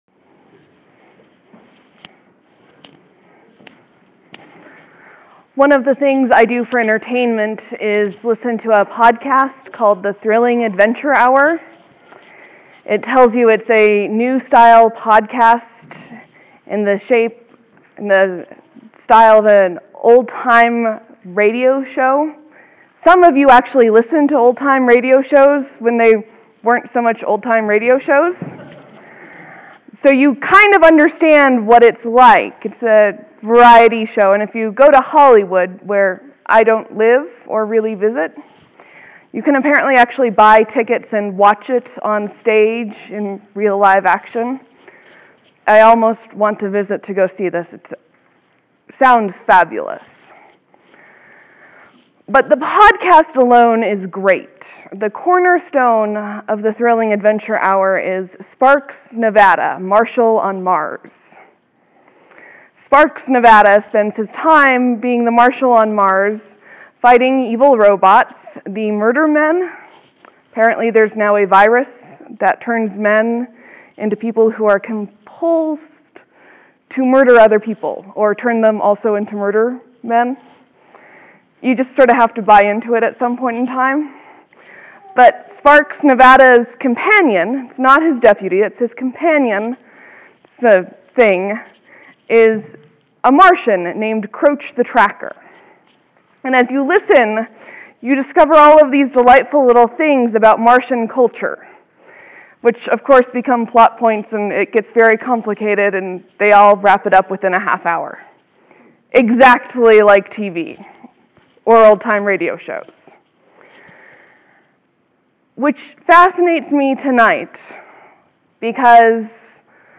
Exposed Feet, a sermon for Maundy Thursday 3-24-13
This was an ecumenical service with the Methodist congregation who shares their building with us.